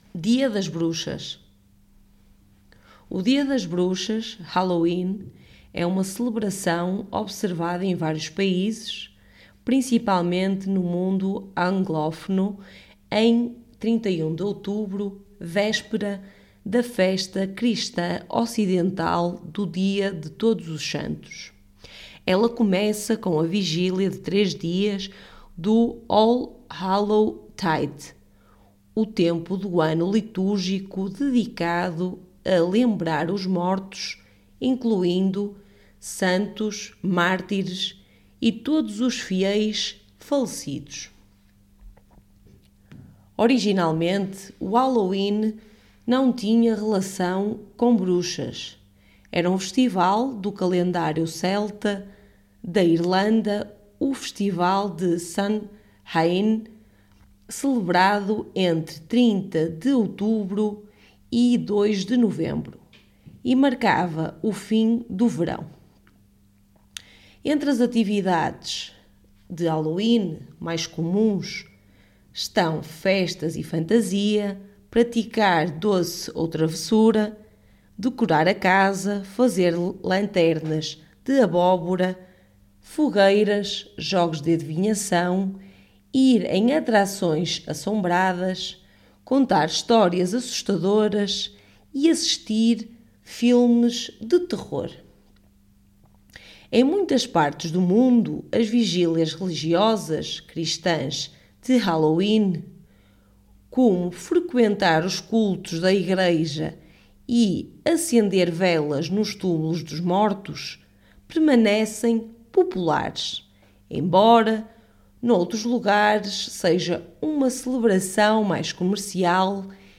Our article text and audio are specifically aimed towards the Portuguese language from Portugal, and not from Brazil or other Portuguese speaking countries.